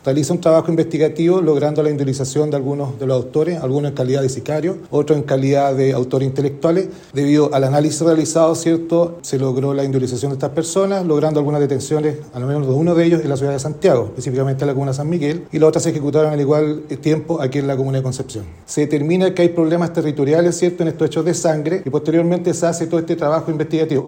El subprefecto Juan Fonseca, jefe de la prefectura de la PDI en Concepción, informó que en el trabajo investigativo se logró identificar a los responsables, algunos en calidad de sicarios y otros de autores intelectuales.